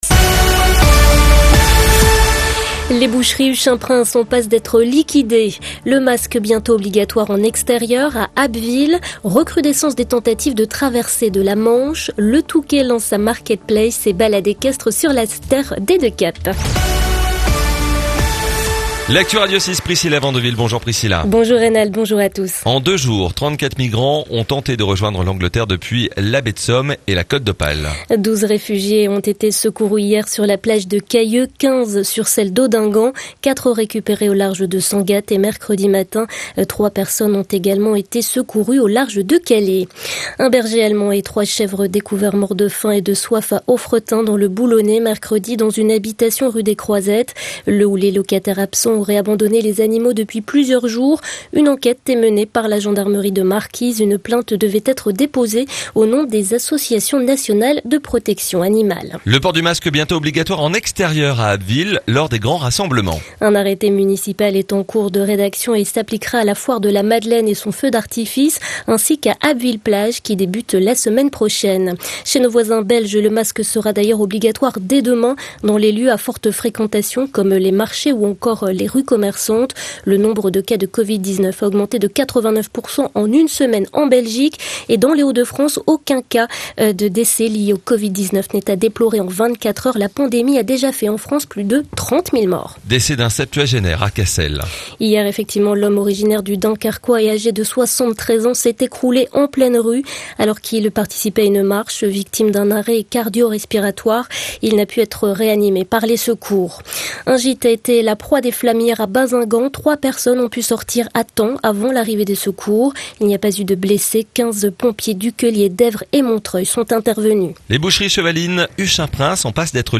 Le journal de la Côte d'Opale et de la Côte Picarde du vendredi 24 juillet